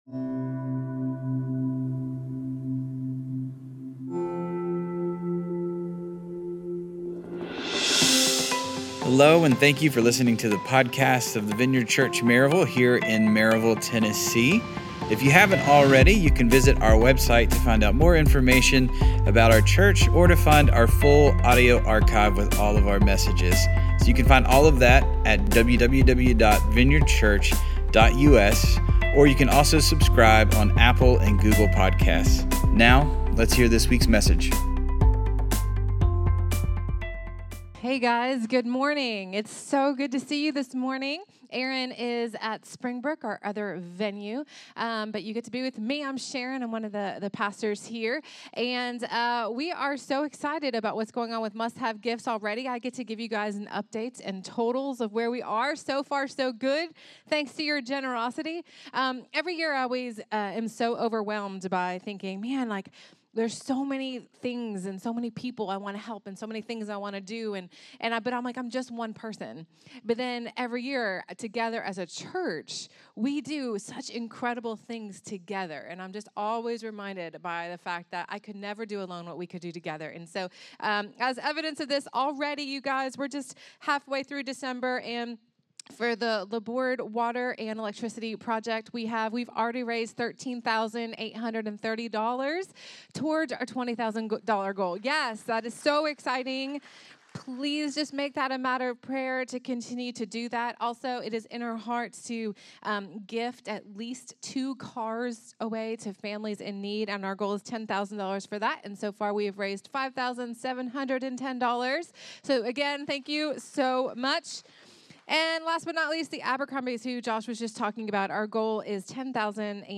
A message from the series "Fall On Your Knees." True joy is an amazing supernatural experience that only God can provide.